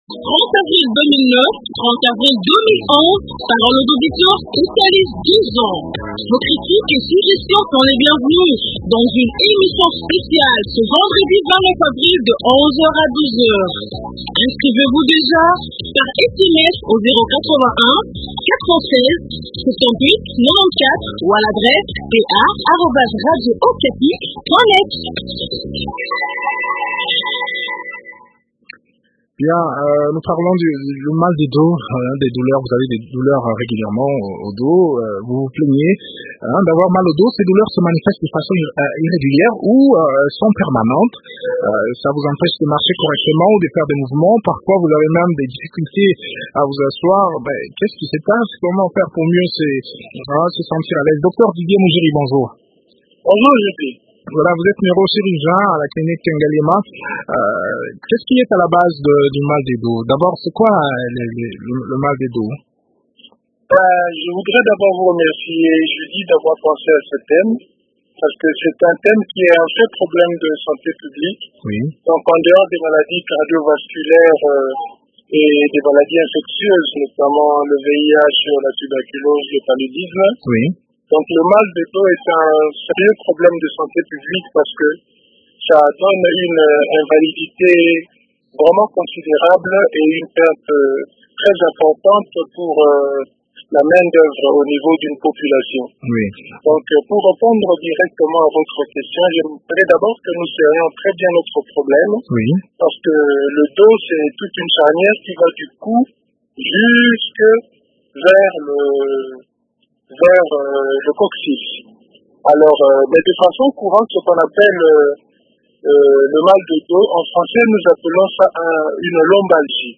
Des éléments de réponse dans cet entretien